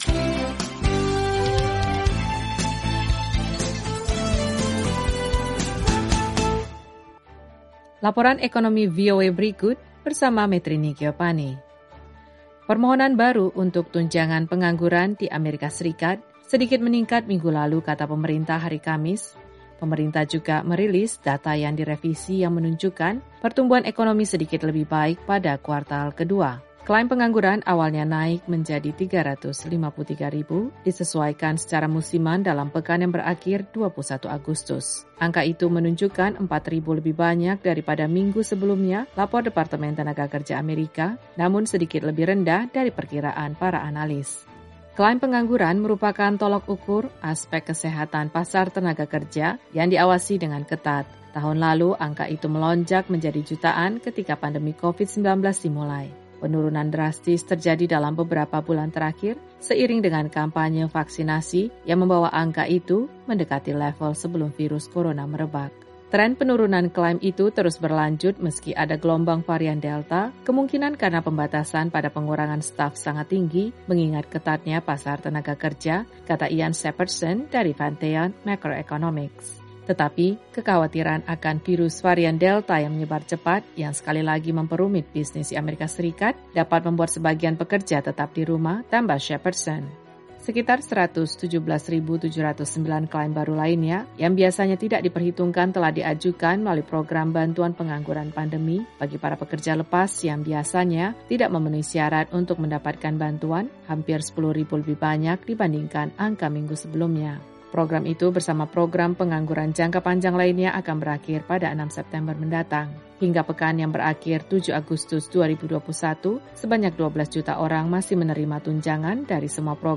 Laporan Ekonomi VOA hari ini mengenai klaim pengangguran AS sedikit bertambah dan pertumbuhan ekonomi tetap kuat. Simak juga laporan terkait sejumlah bank dibuka kembali di Afghanistan.